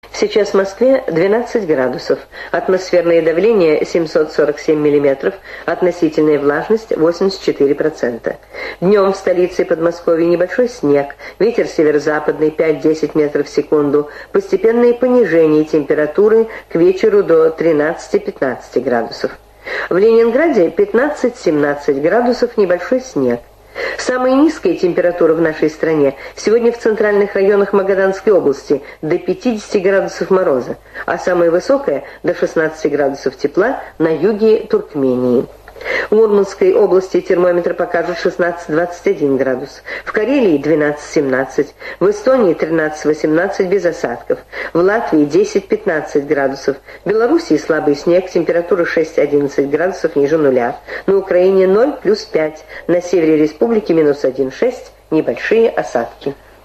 Прогноз погоды в эфире радио СССР